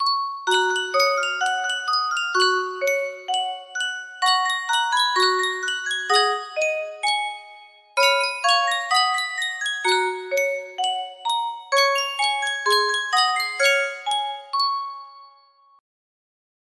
Sankyo Music Box - Sweetest Story Ever Told RFS music box melody
Full range 60